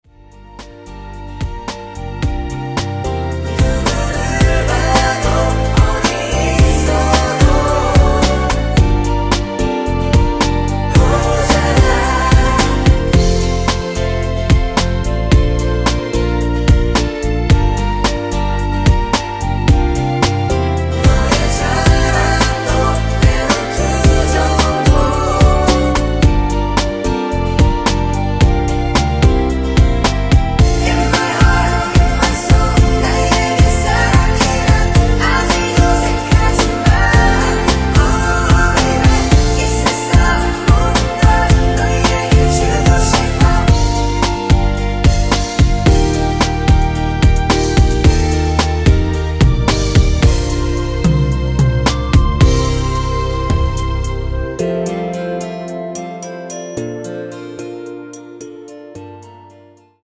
원키에서(+2)올린 멜로디와 코러스 포함된 MR입니다.(미리듣기 확인)
Eb
앞부분30초, 뒷부분30초씩 편집해서 올려 드리고 있습니다.
중간에 음이 끈어지고 다시 나오는 이유는